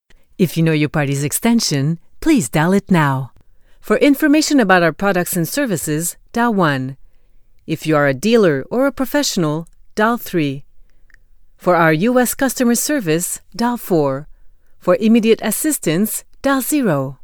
Message téléphonique